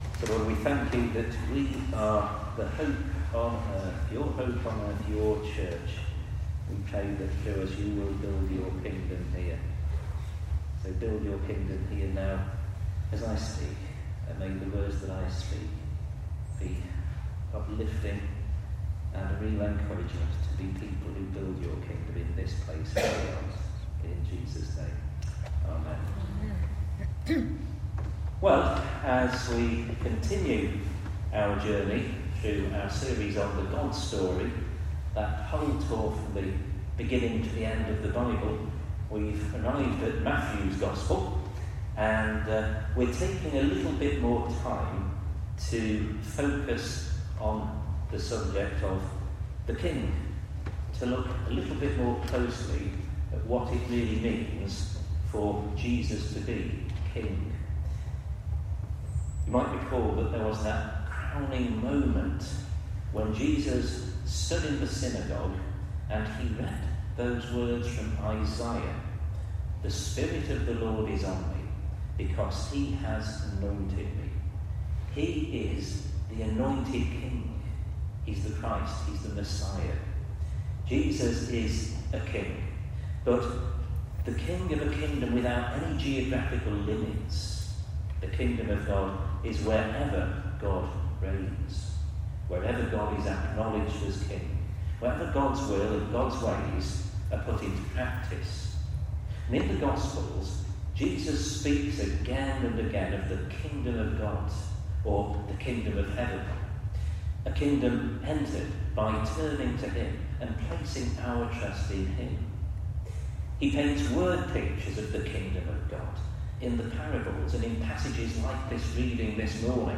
Downloadable sermons from St Matthew's